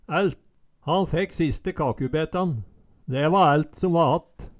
ælt - Numedalsmål (en-US)
Høyr på uttala Ordklasse: Determinativ (mengdeord) Attende til søk